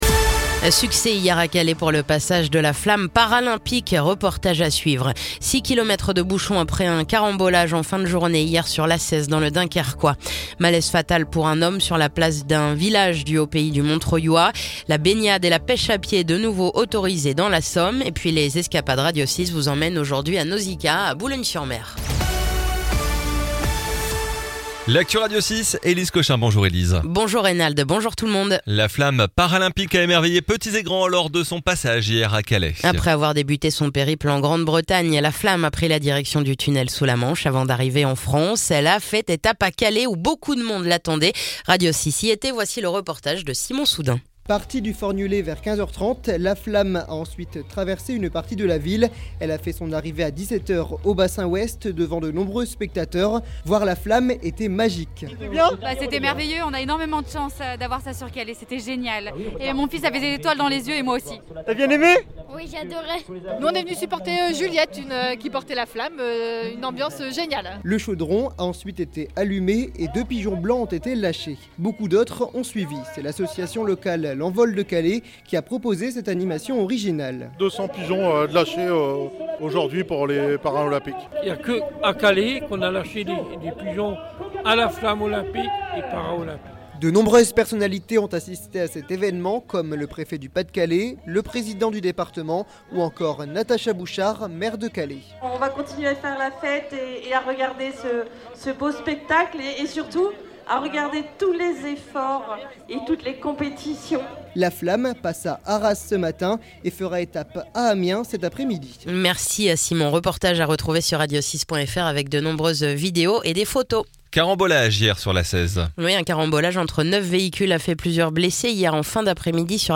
Le journal du lundi 26 août
(journal de 9h)